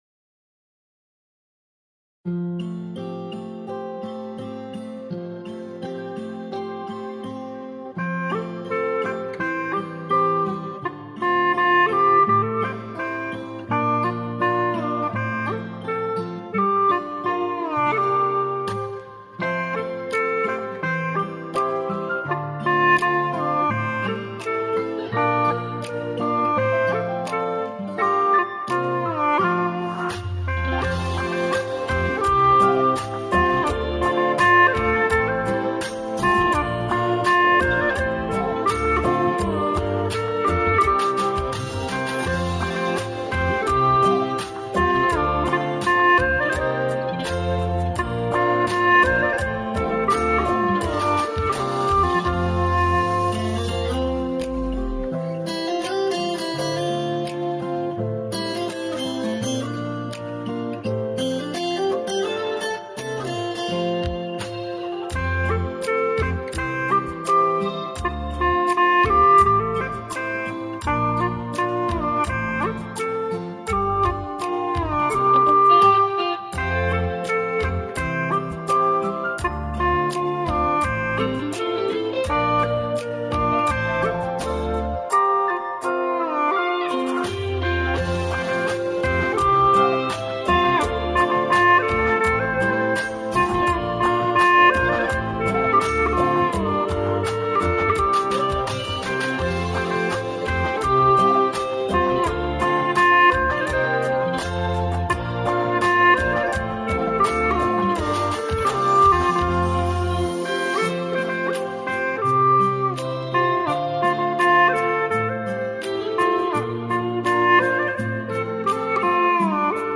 调式 : F 曲类 : 流行